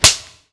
Shout.wav